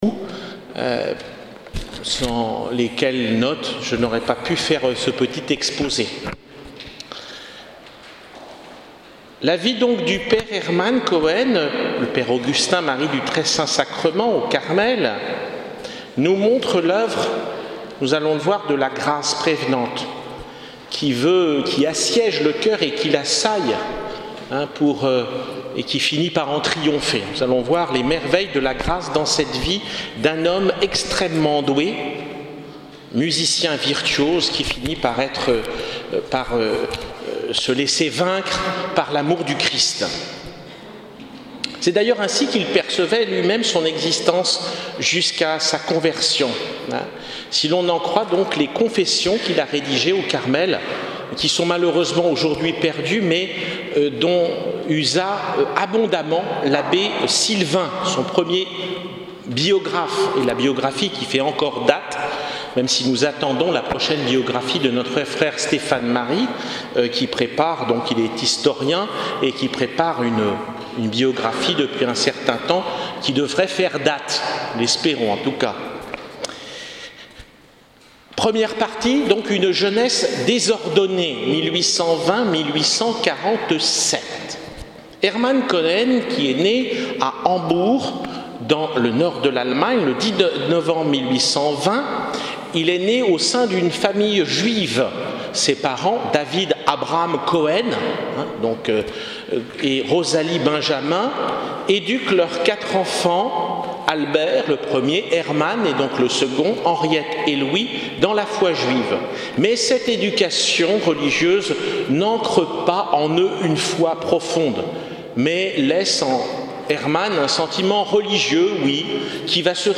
lors des vendanges spirituelles du dimanche 2 octobre 2016